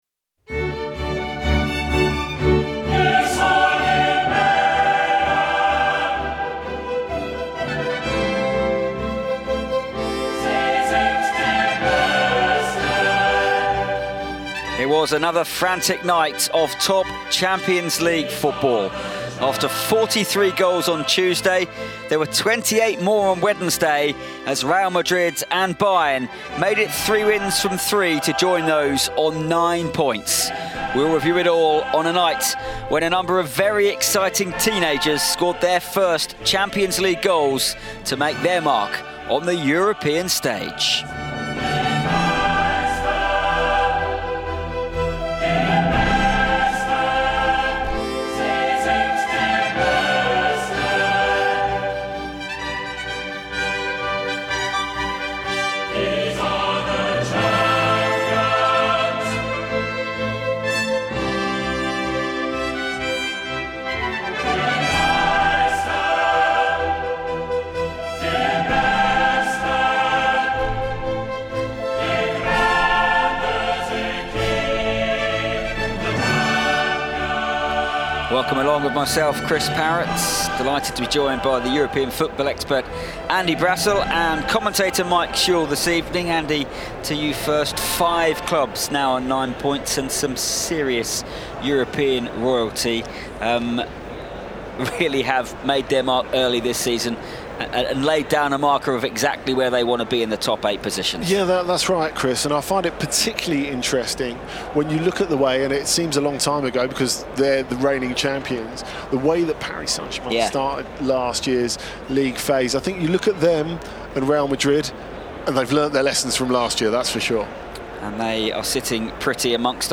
Madrid midfielder Arda Guler reflects on the match that saw Los Blancos maintain their perfect start to the League Phase.
We hear from Reds striker Hugo Ekitike, who shares what it was like scoring against his former club in Germany. Elsewhere, Victor Osimhen got two goals in a 3-1 win for Galatasaray over Bodo/Glimt – he reflects on his performance on the show.